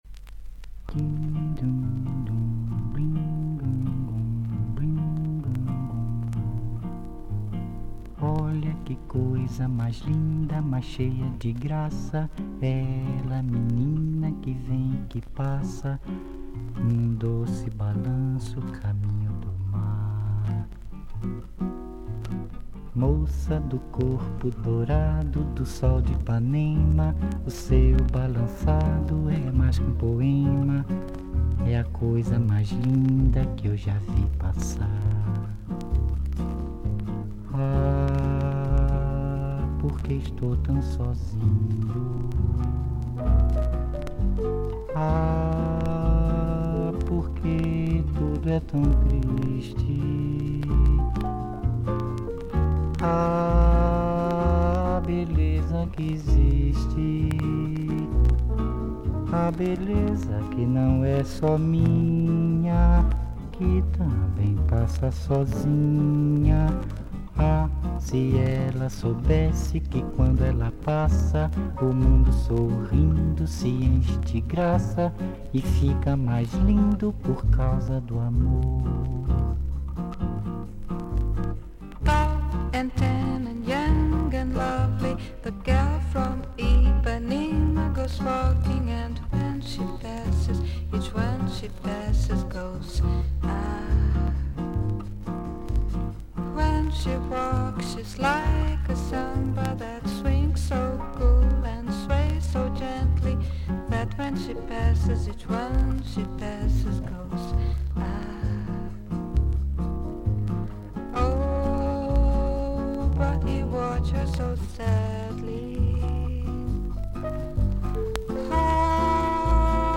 ボサ・ノヴァ/サンバ・ブームを作った名アルバム。
VG+ 少々軽いパチノイズの箇所あり。少々サーフィス・ノイズあり。